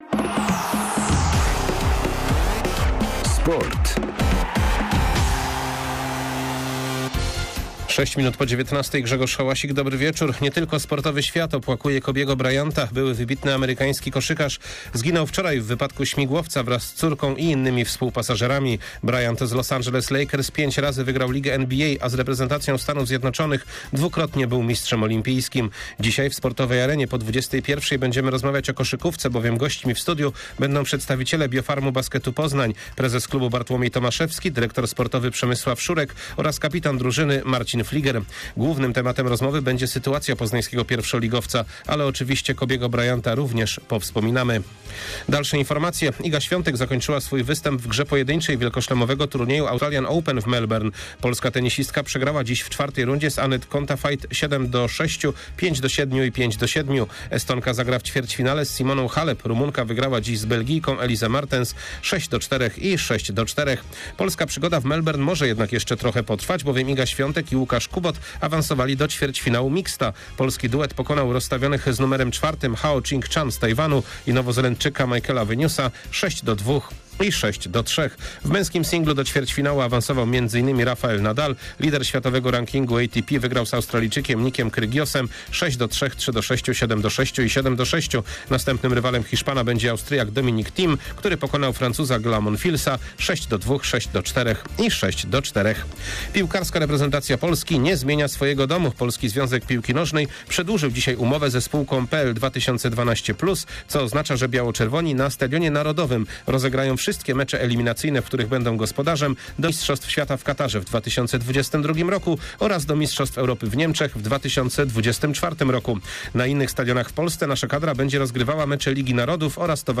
27.01. SERWIS SPORTOWY GODZ. 19:05